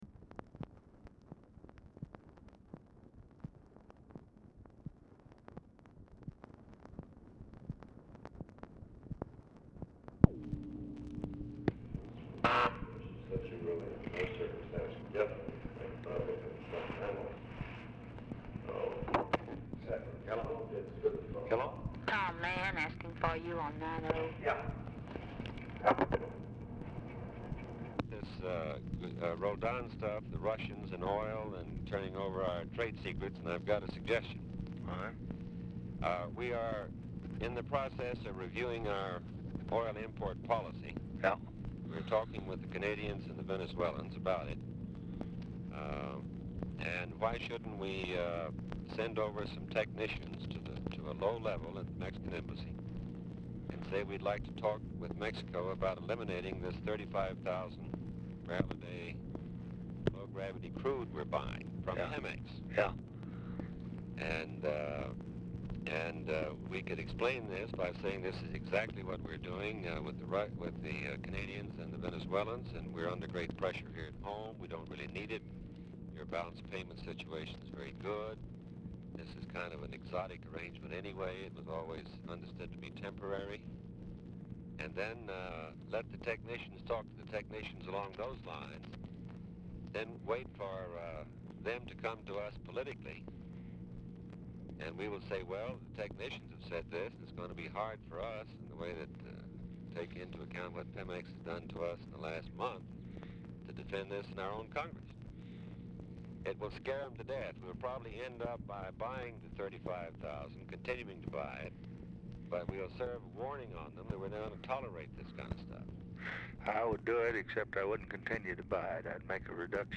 BRIEF OFFICE CONVERSATION PRECEDES CALL
Format Dictation belt
Location Of Speaker 1 Oval Office or unknown location
Specific Item Type Telephone conversation